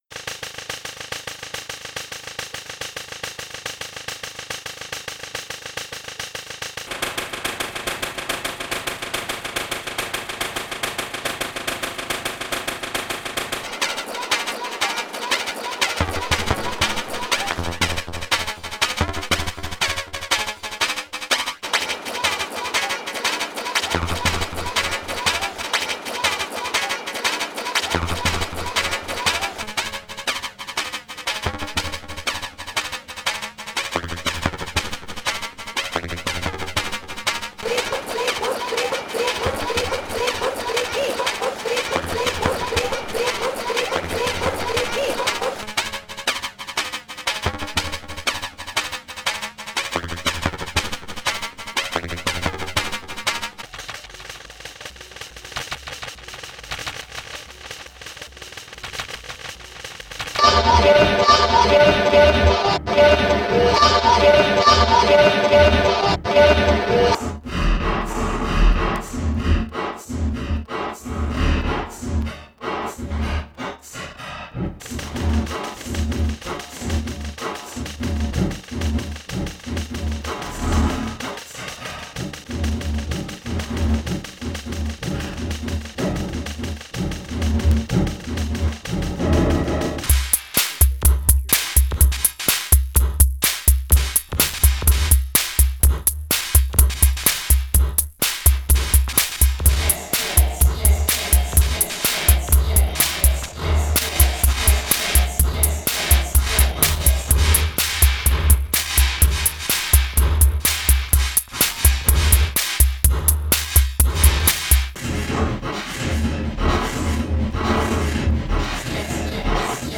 Heavy on the white noise with no softsynth "little melodies." Made with low-res synth beats, vocal samples, and semi-ambient noise triggered by a Eurorack (analog) sequencer, then recorded, timestretched, and further edited in Tracktion's Waveform DAW running on a Linux PC.
Most of the audio comes from ADDAC Systems' Wav and Ultra Wav sample players, which handle beats with reasonable timing as well as random blorts triggered by cv inputs. Other Eurorack devices used are Doepfer's A-154/155 sequencer and VC-DSP effects module (for reverb and chorus). A "found" drum loop provides additional rhythm.